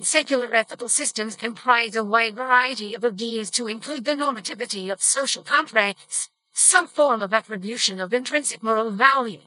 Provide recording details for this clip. I always upsample my audio to 48KHz then treble boost and reduce bass to improve audio quality.